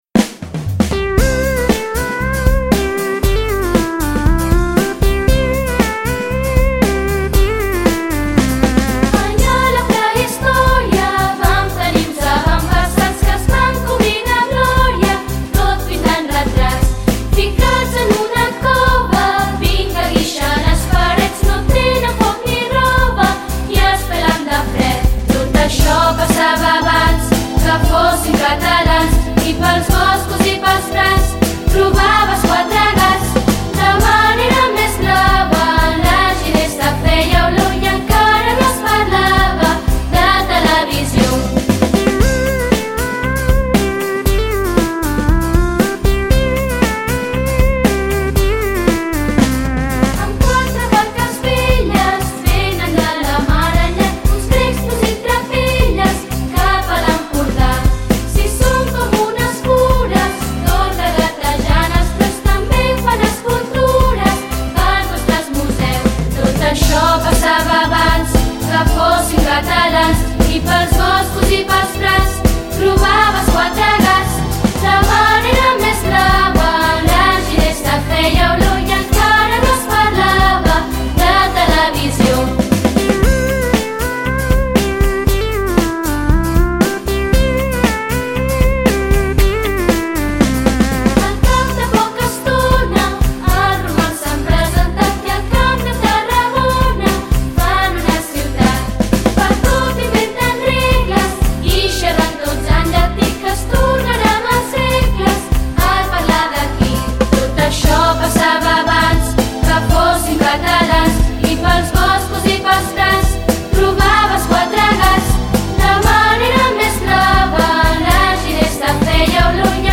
Els nens i nenes de 4t estem cantant la cançó Els avantpassats.